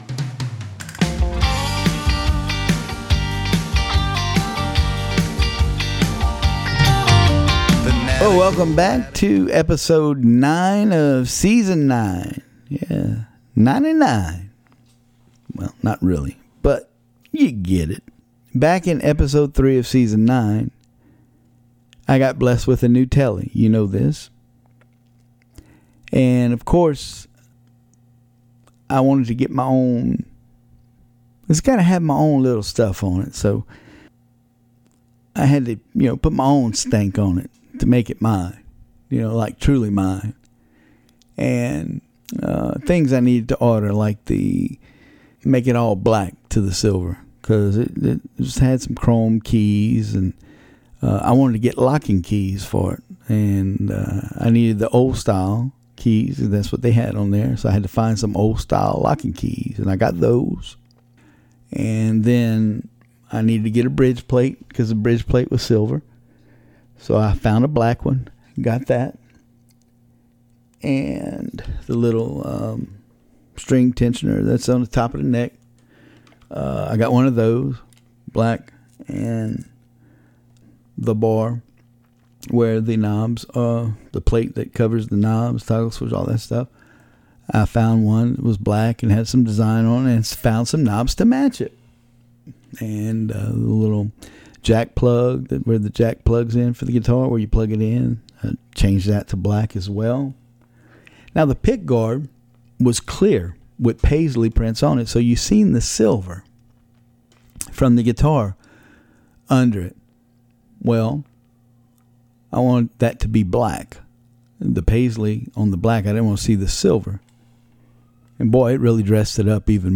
Putting my stank on a new Telecaster and playing the snot out of it.